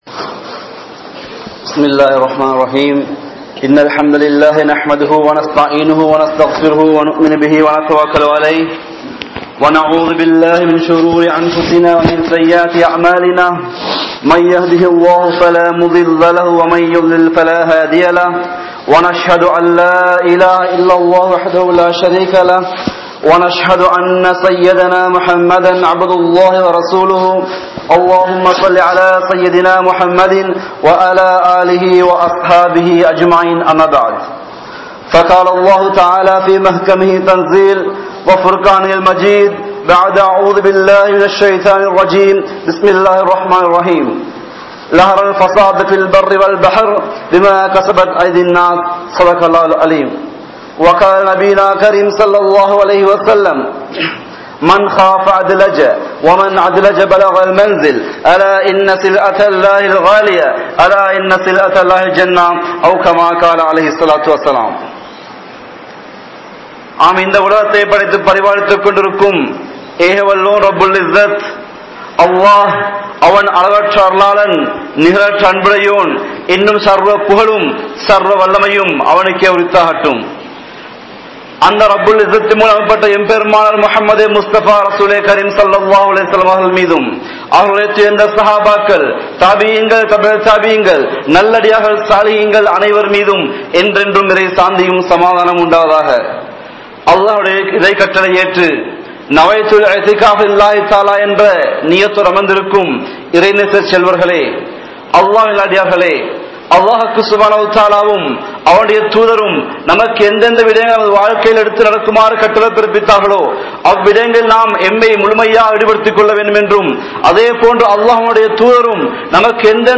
Unmaiyaana Muslim Yaar? (உண்மையான முஸ்லிம் யார்?) | Audio Bayans | All Ceylon Muslim Youth Community | Addalaichenai
Masjidhul Hakam Jumua Masjidh